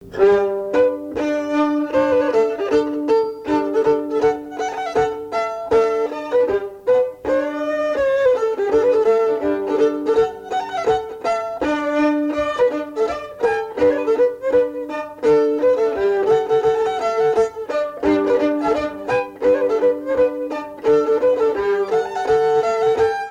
danse : scottich trois pas
Pièce musicale inédite